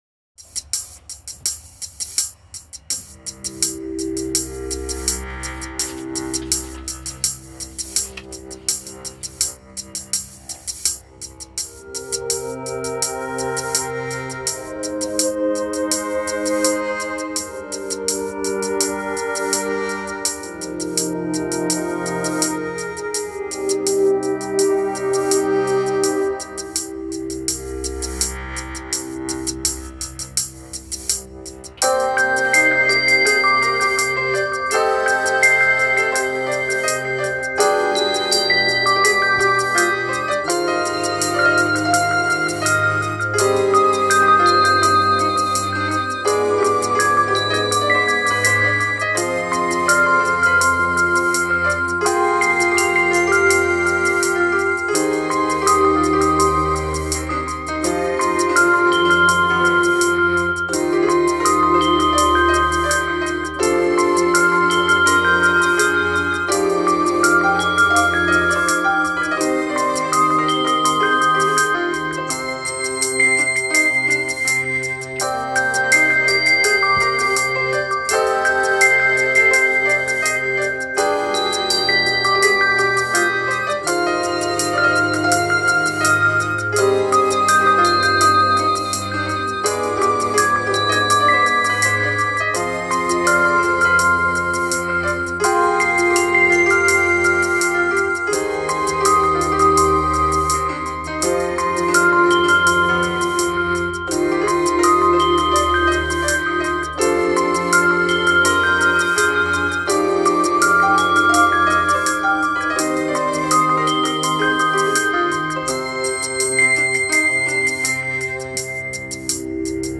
I now use a Casio MZ-2000 and my new Yamaha Genos V2 76-key Synthesiser/Arranger/Workstations to add to my digital recording studio.
ruby-with-piano-lead.m4a